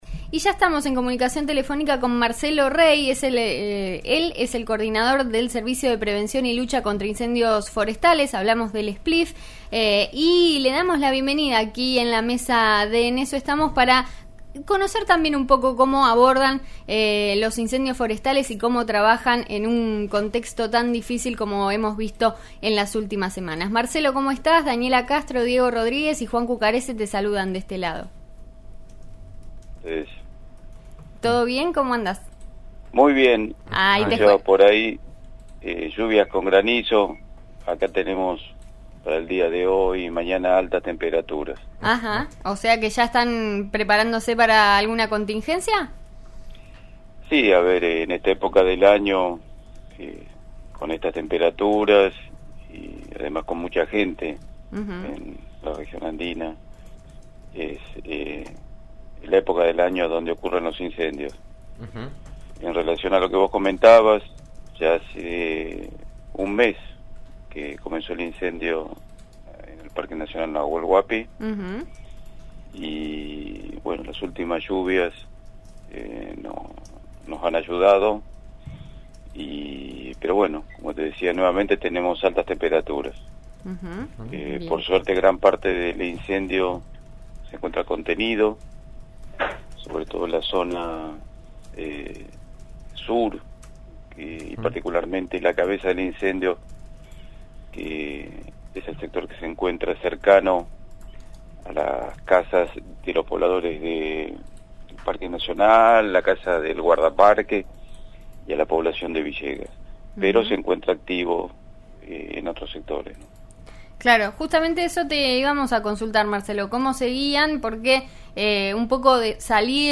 en una entrevista en la que contó cómo fue el combate contra el fuego en las últimas semanas.